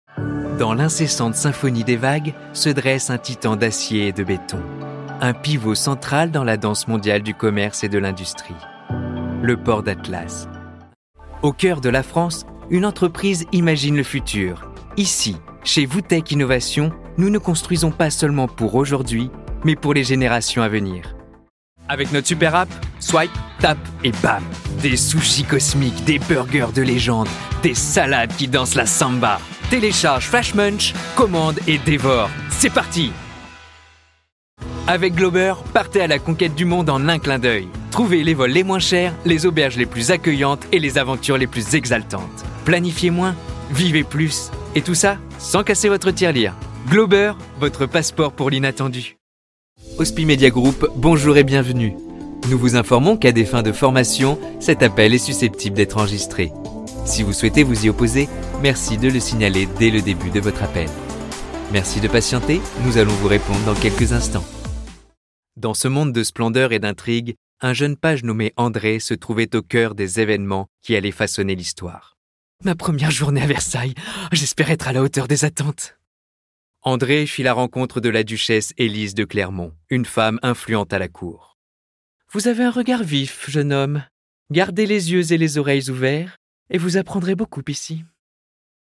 Bande démo
Démos Voix-off : documentaire, instititutionnel, pub, attente téléphonique, livre-audio